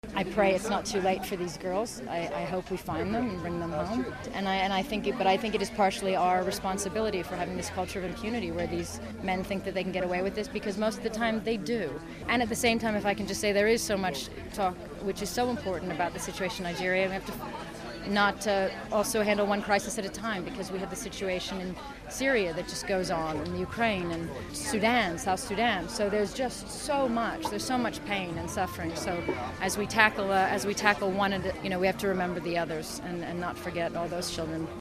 Speaking to us on the blue carpet, Angelina - who is a UN Envoy - says we need tackle multiple crises in the world.